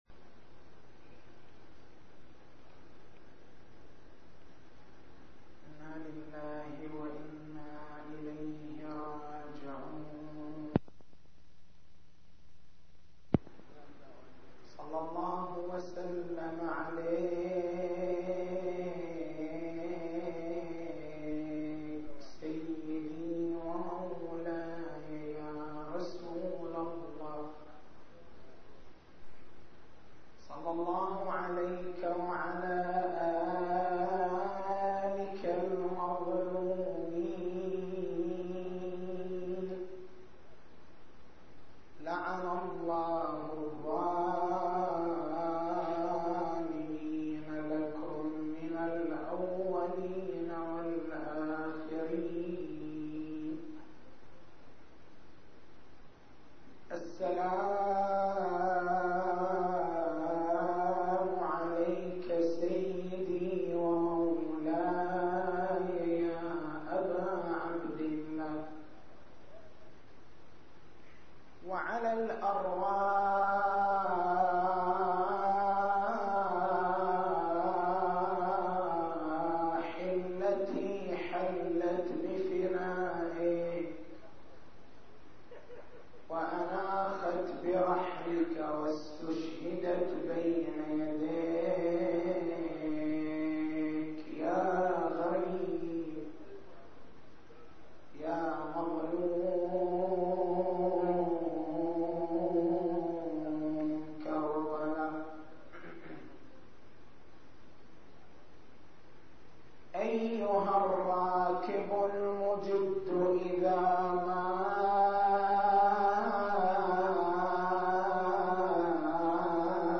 تاريخ المحاضرة: 13/01/1423 نقاط البحث: كيفية الصلاة على النبي وآله وجه التشبيه بالصلاة الإبراهيمية ثمرات الصلاة على النبي وآله التسجيل الصوتي: تحميل التسجيل الصوتي: شبكة الضياء > مكتبة المحاضرات > محرم الحرام > محرم الحرام 1423